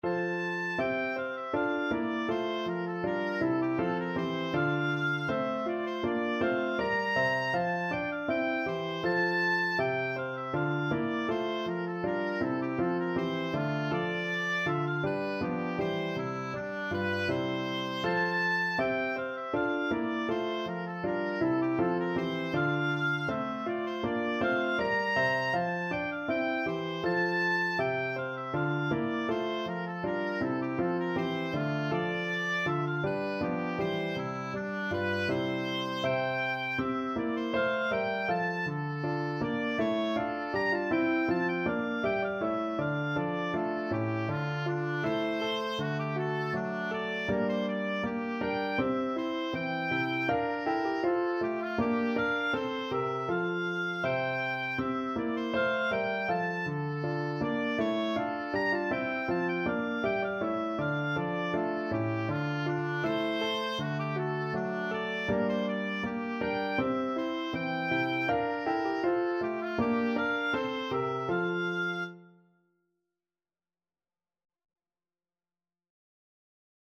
Oboe
=c.80
3/2 (View more 3/2 Music)
F major (Sounding Pitch) (View more F major Music for Oboe )
Classical (View more Classical Oboe Music)